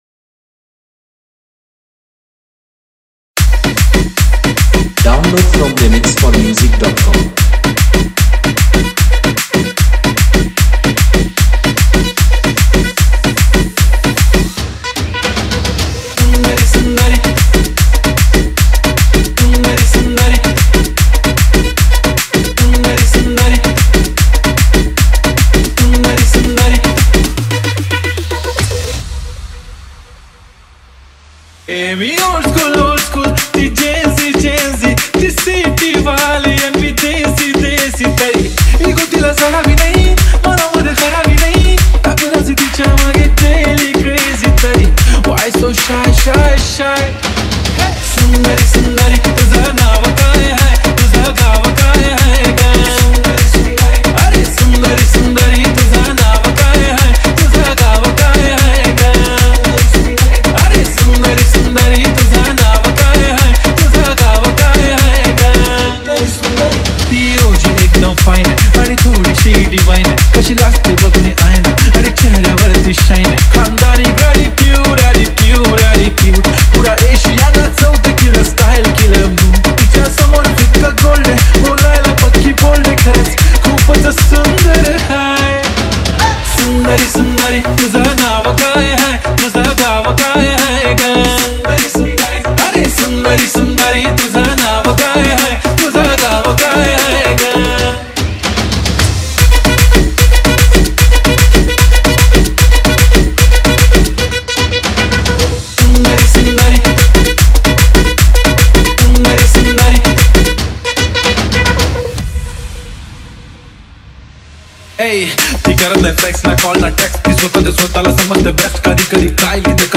Category : Others DJ Remix